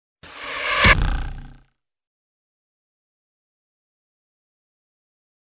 Поражение мишени